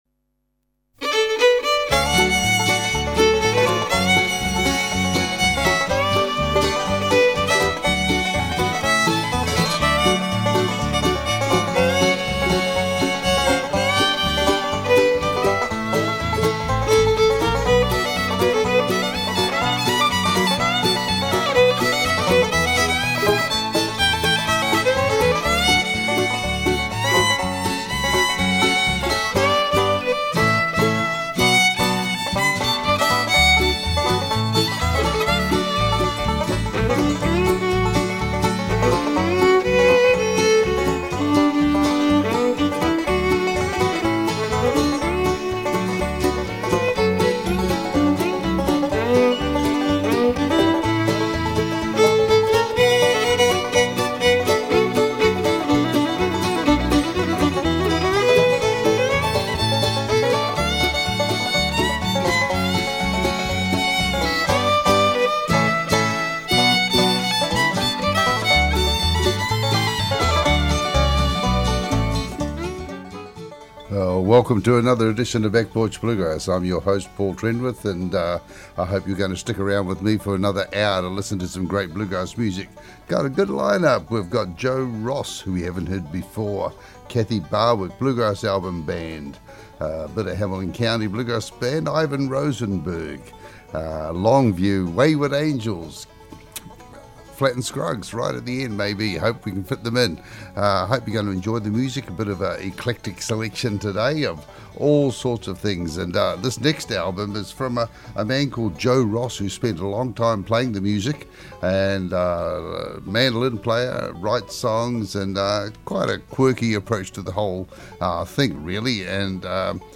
Back Porch Bluegrass Show - 31 January 2017